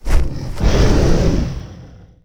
wav / general / combat / creatures / dragon / he / attack2.wav